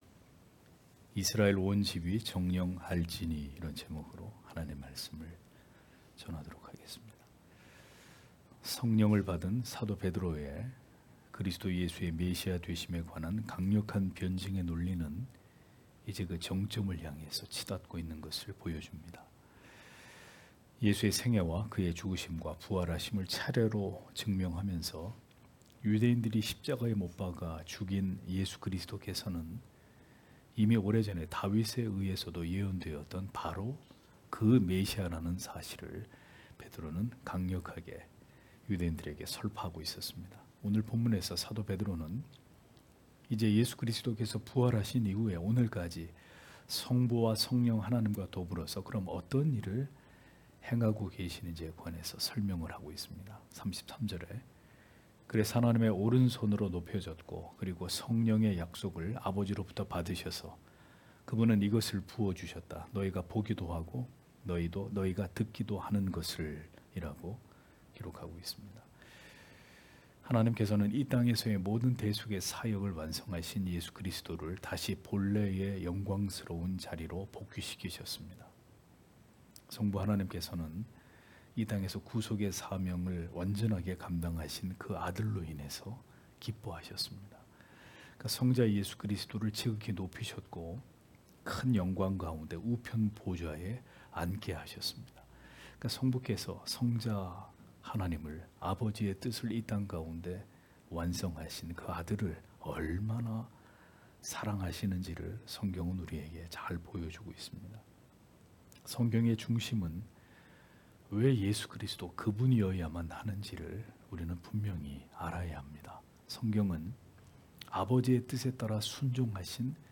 금요기도회 - [사도행전 강해 16] 이스라엘 온 집이 정녕 알찌니 (행 2장 33- 36절)